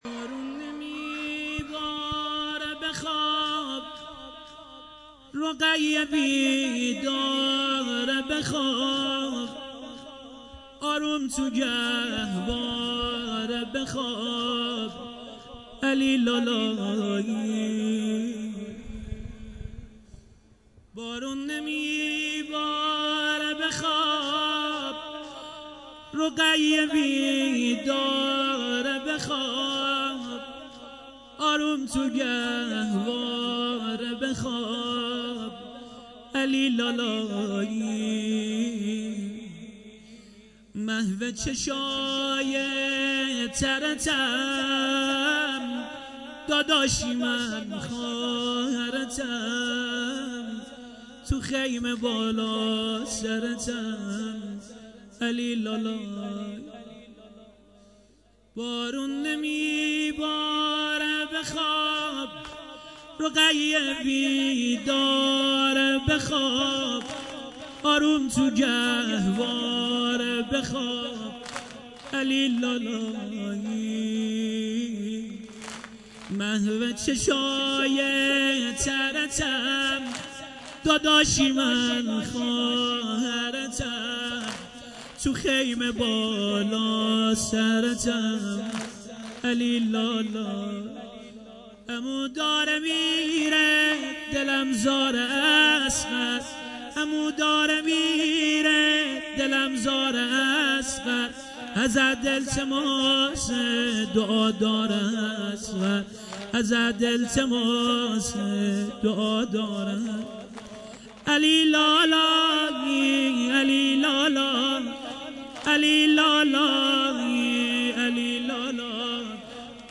واحد - بارون نمی باره بخواب رقیه بیداره بخواب